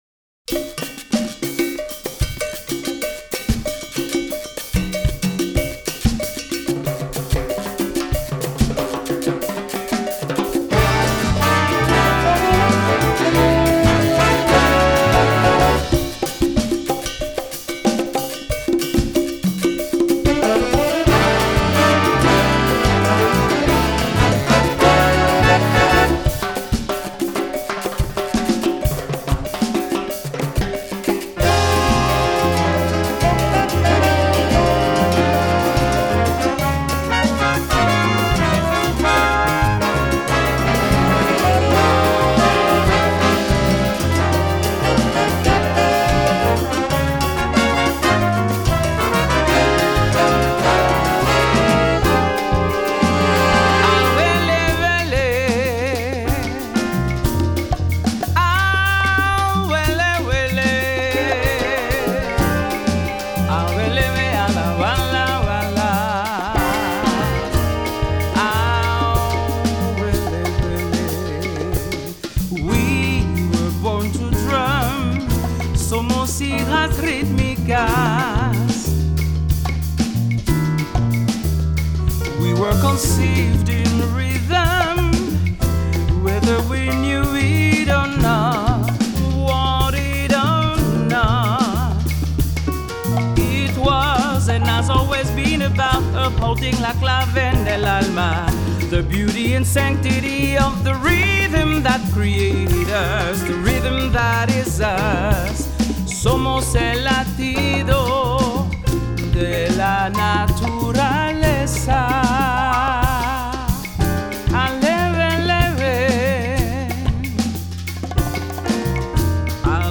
FILE: Latin Jazz Vocal